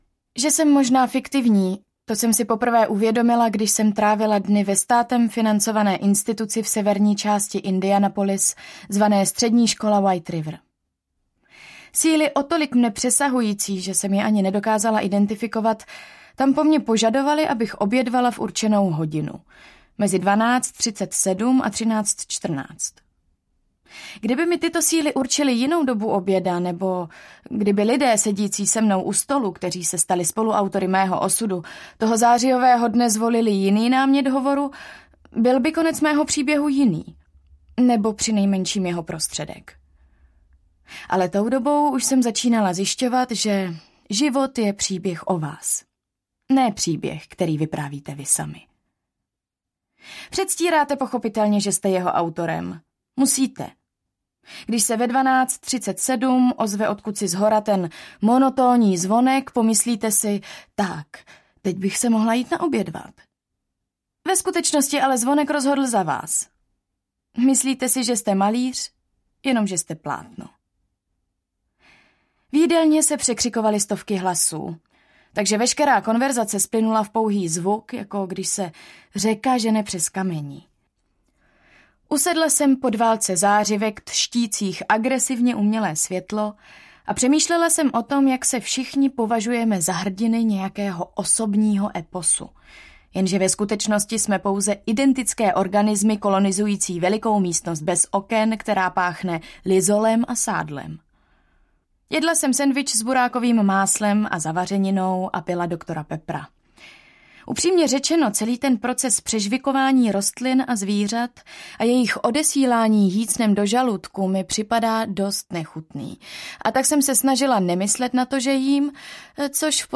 Jedna želva za druhou audiokniha
Ukázka z knihy